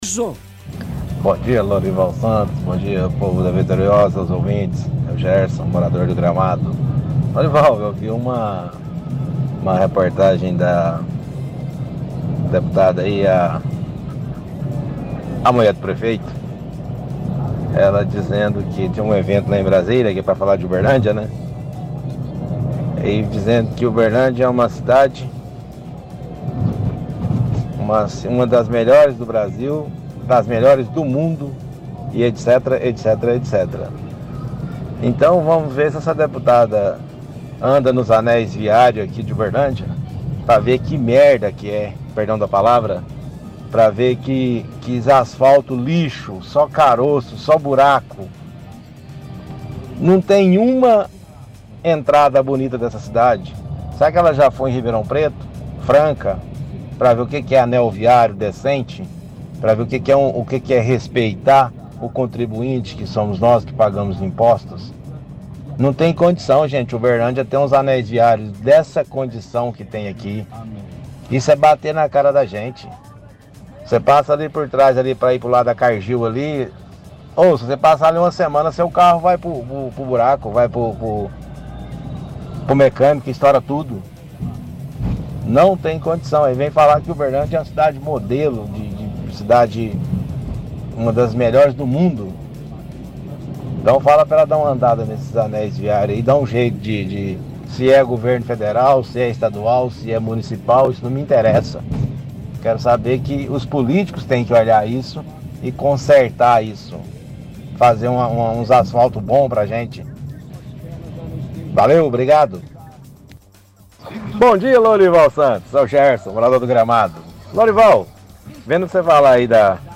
– Ouvinte diz que viu uma matéria com a deputada federal Ana Paula Junqueira, se referindo à homenagem que Uberlândia recebeu em Brasília e critica fala da deputada que Uberlândia é uma excelente cidade, fala que não existe uma entrada bonita para a cidade, reclama dos anéis viários de Uberlândia por conta da quantidade de buracos.